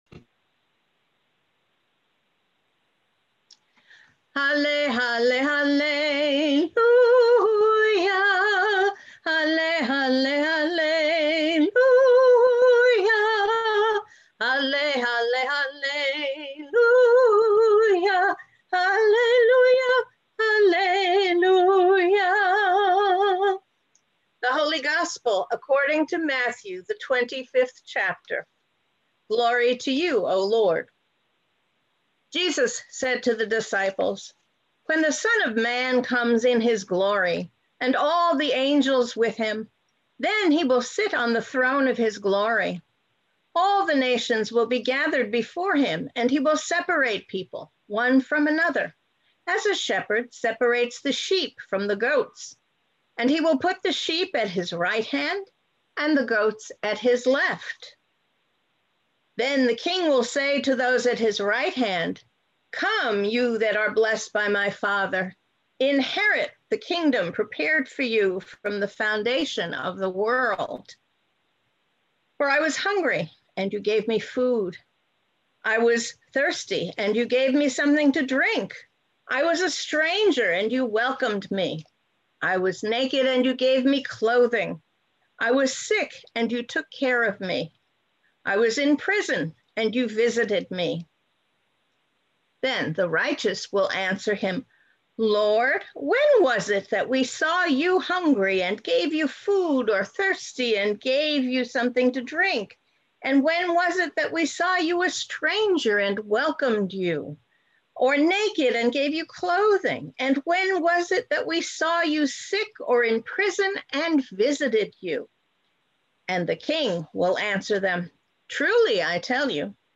Sermons | Lutheran Church of the Epiphany and Iglesia Luterana de la Epifania
Christ the King Sunday